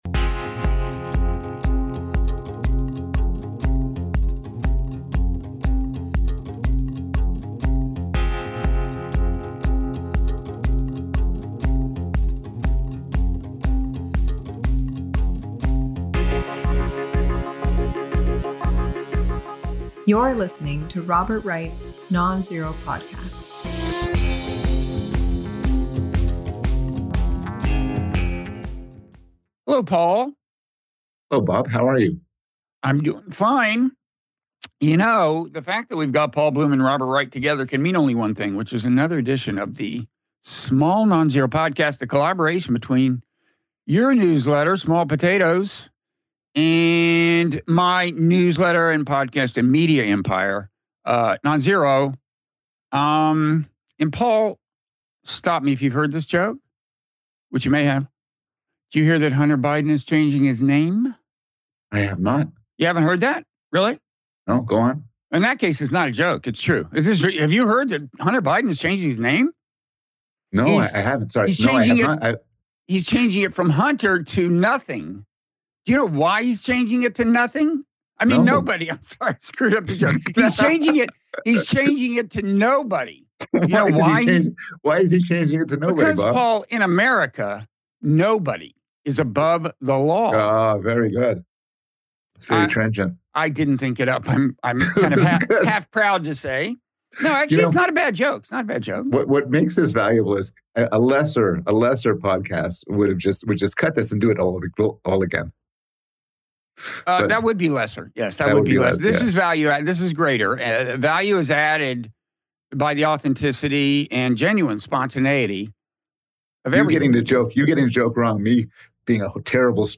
Debating the Hunter Biden Pardon (Robert Wright & Paul Bloom) (Robert Wright interviews Paul Bloom; 05 Dec 2024) | Padverb